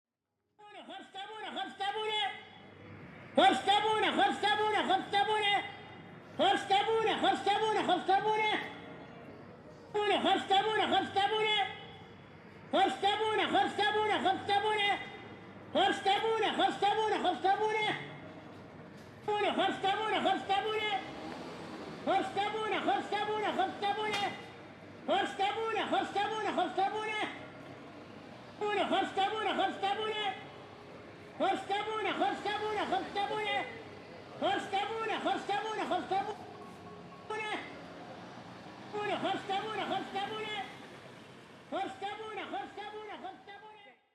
Street sellers in Mahdia